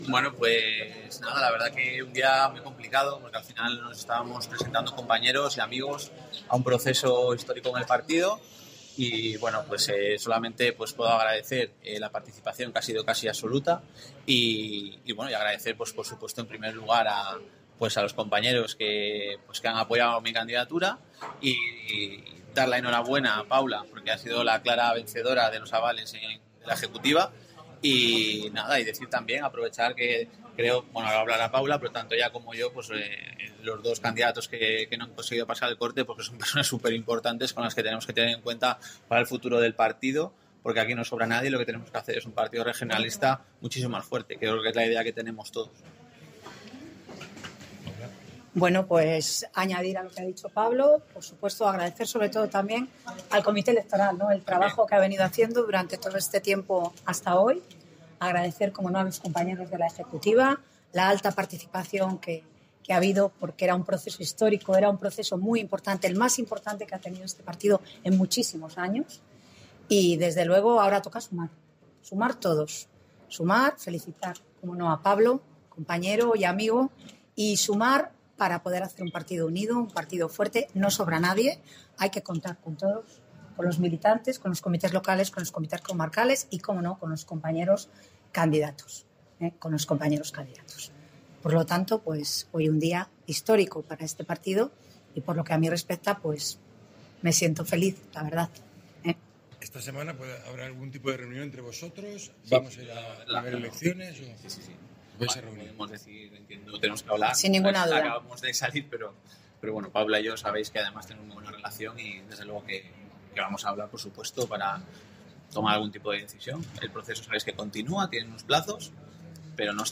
Declaraciones Paula Fernández y Pablo Diestro